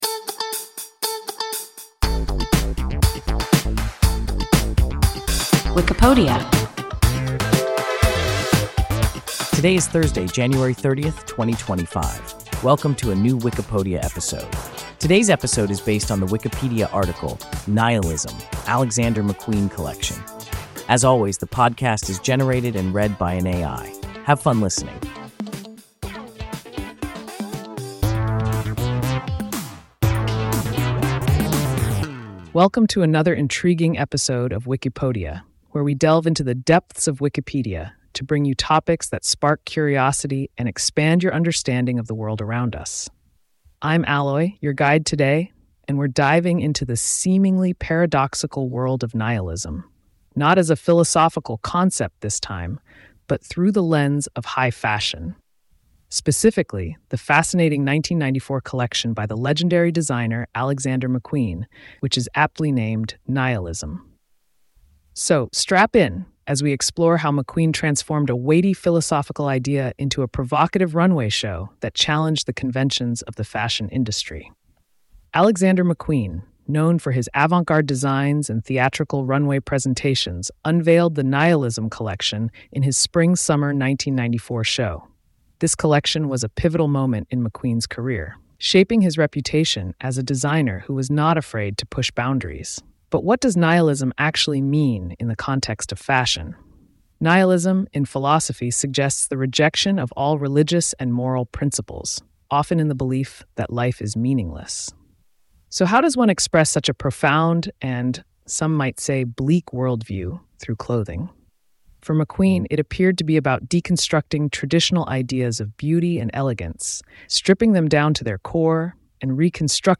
Nihilism (Alexander McQueen collection) – WIKIPODIA – ein KI Podcast